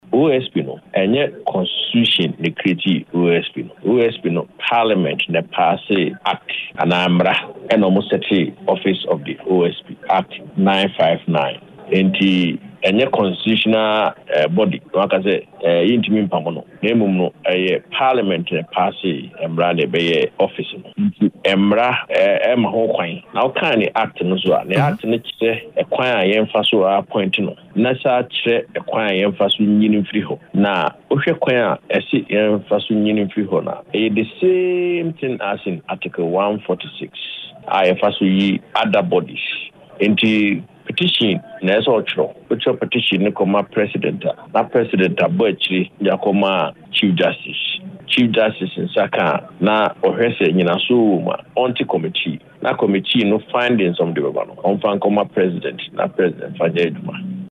In an interview
on Ahotor FM